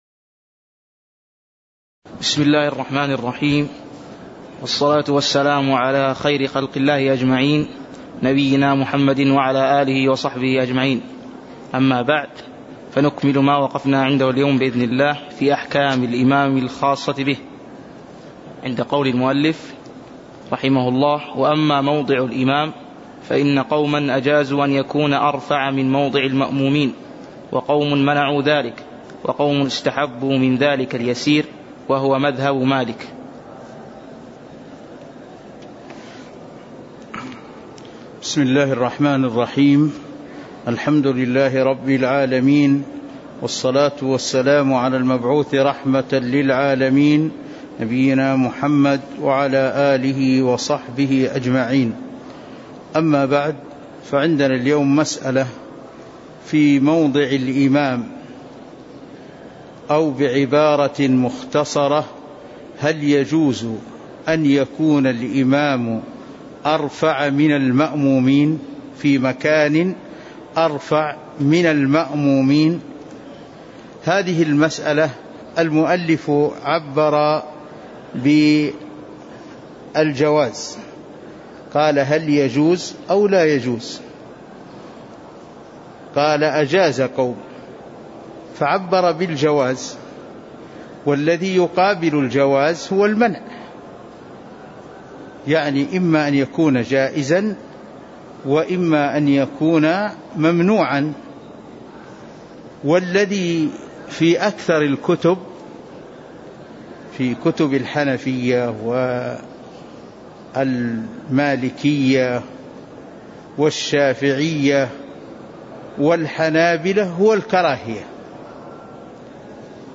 تاريخ النشر ١١ ربيع الأول ١٤٤٣ هـ المكان: المسجد النبوي الشيخ